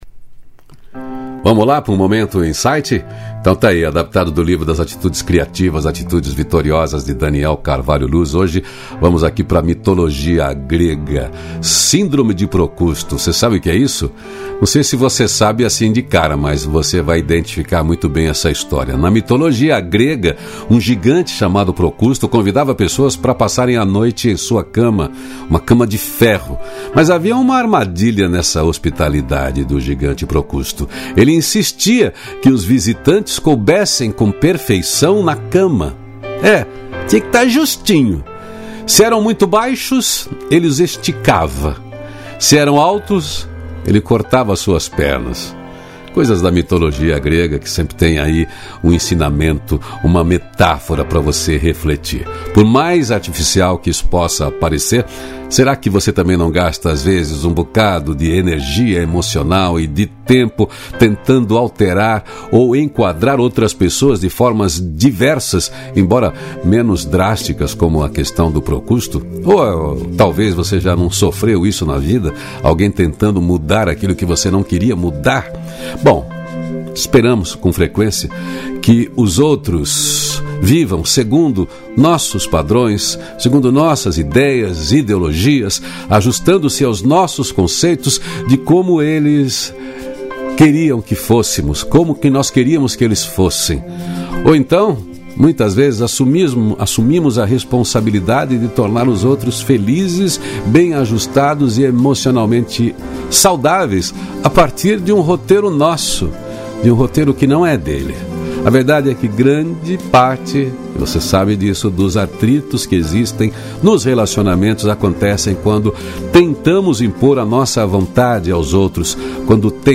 Música: Não há pedras no caminho… Banda Aquática Álbum: Nova Manhã